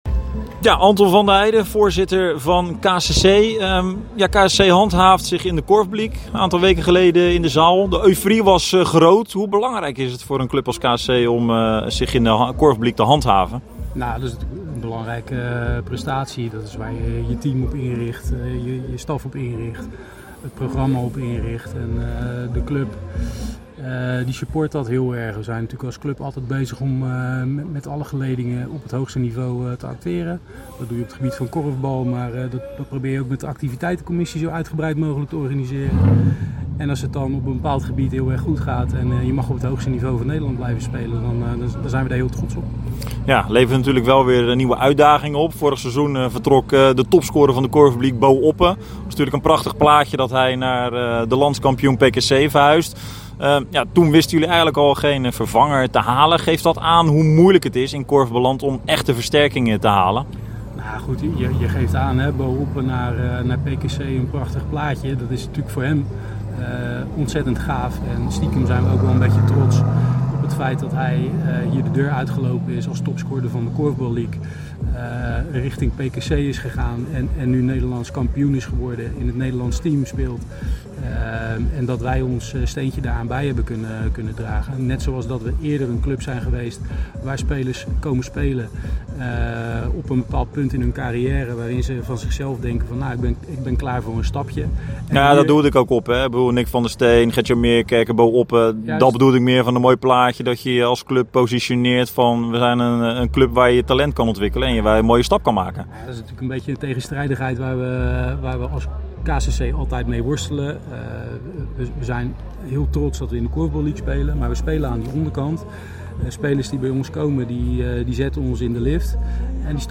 *excuses voor het zachtere geluid